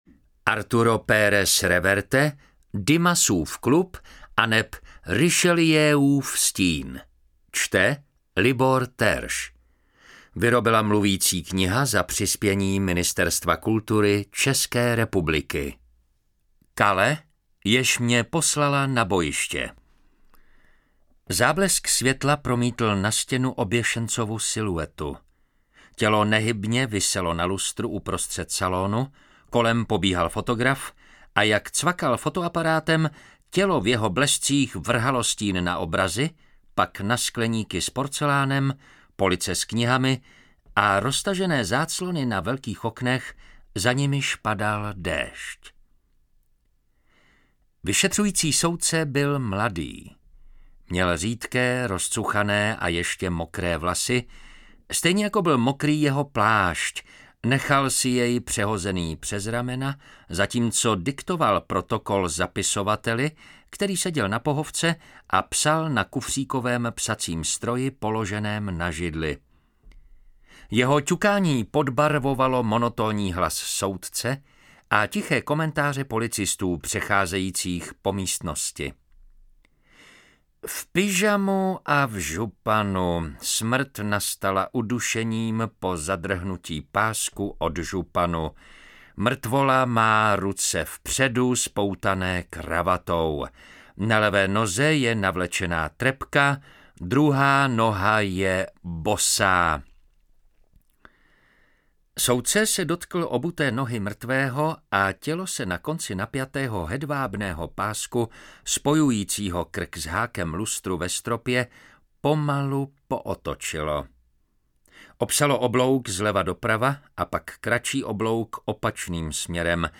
Čte: